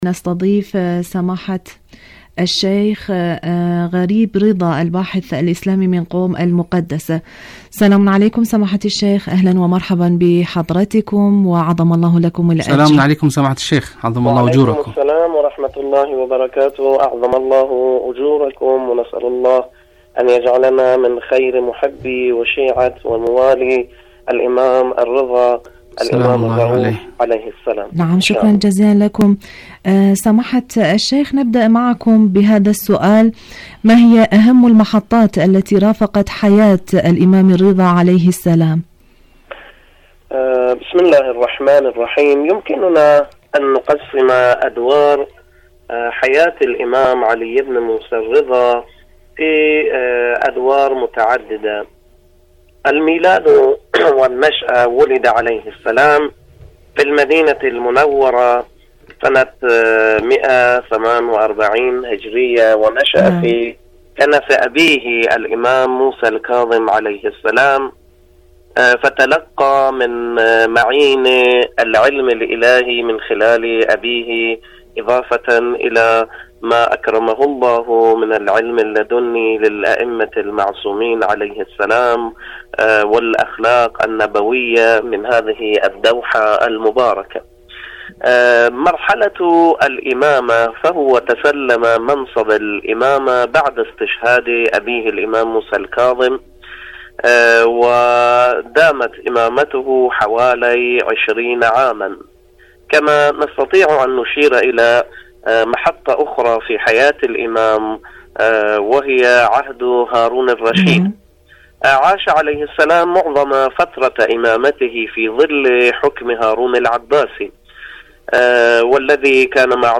إذاعة طهران العربية مقابلات إذاعية